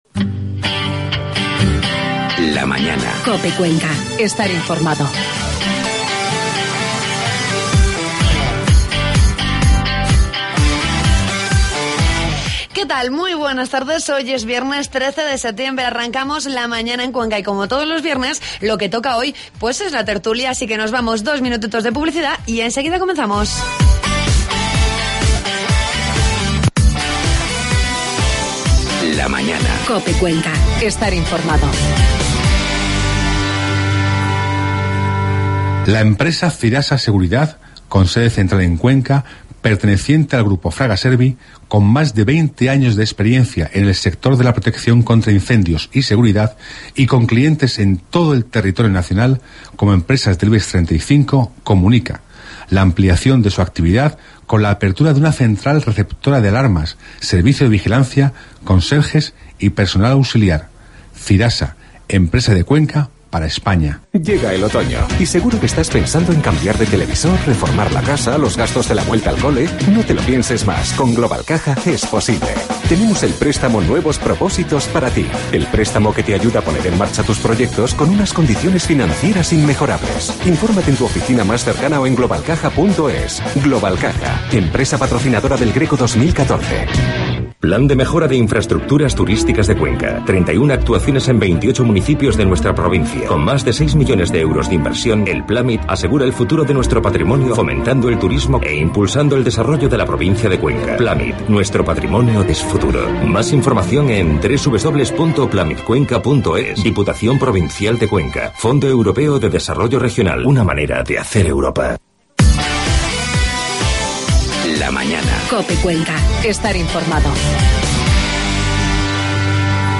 AUDIO: Ya puedes escuchar la tertulia de COPE Cuenca de este viernes 13 de septiembre